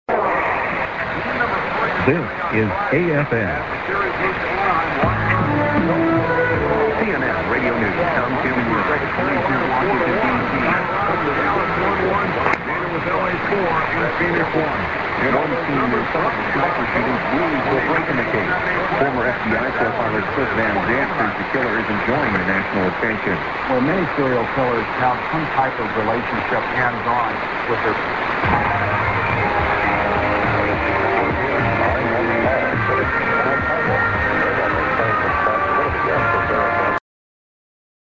ID(man:This is AFN)->CNN news->music //1530